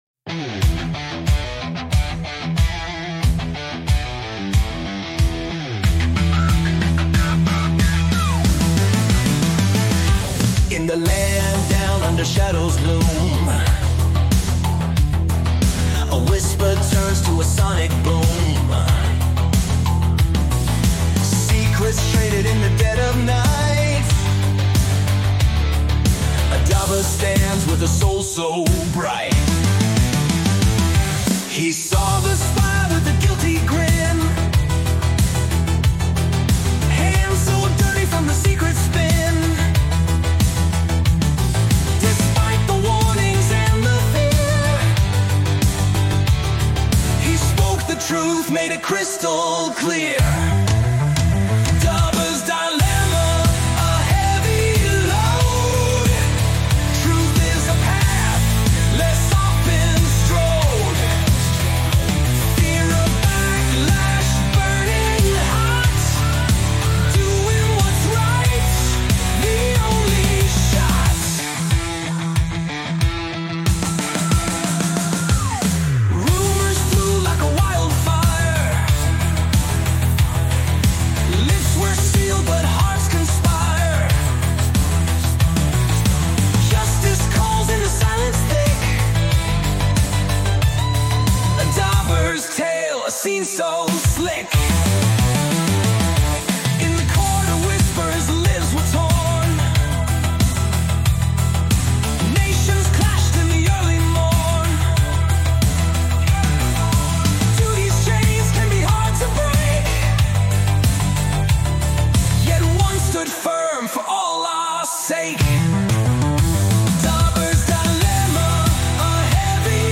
edgy rock